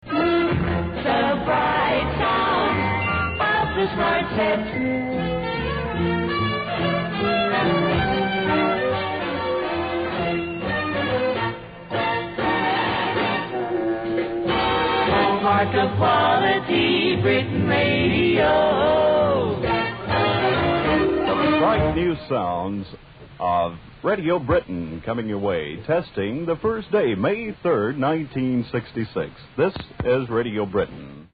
Replete with Light music, backed by dollars, and fuelled by American formatics, Britain Radio launched proudly in 1966; becoming Radio 355 in early 1967, amidst all the familiar wranglings of the era.